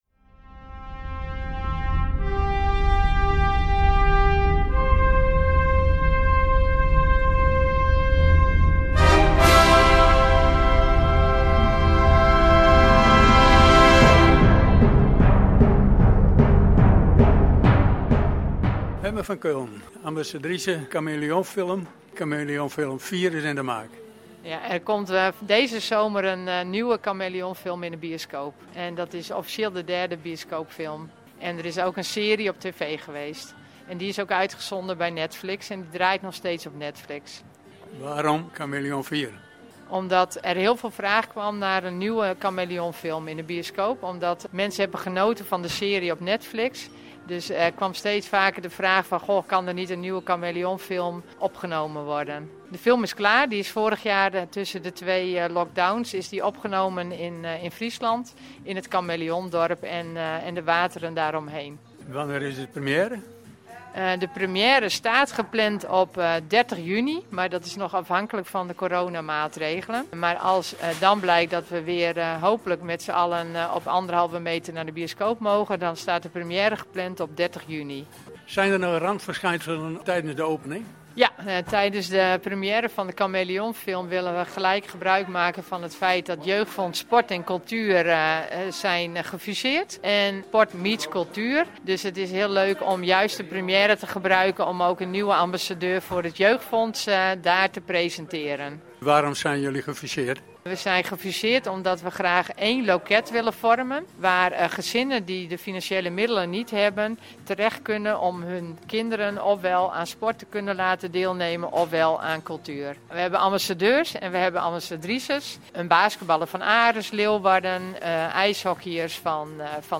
Underweis - Interviews - Sport - Onderweg